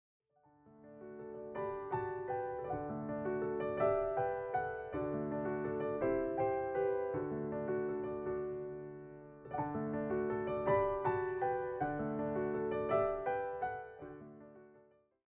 all given a solo piano treatment.